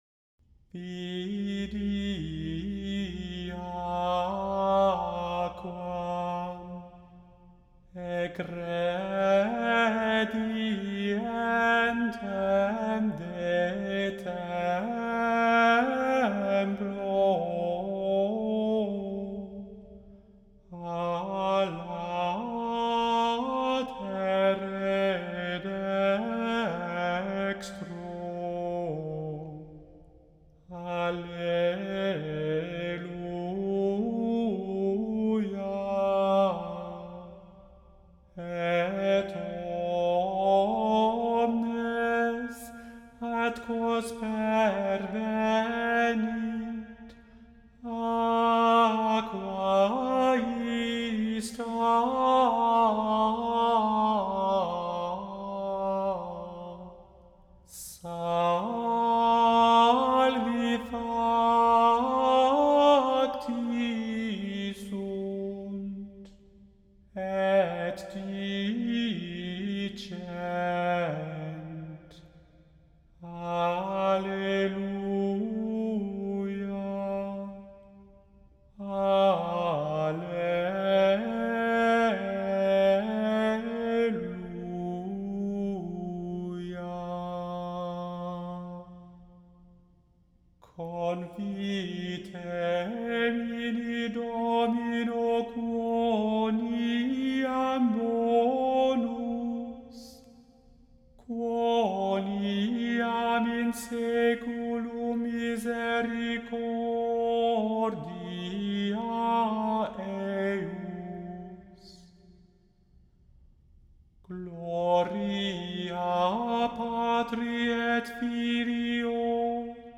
The Chant Project – Chant for Today (July 17) – Vidi aquam (version II)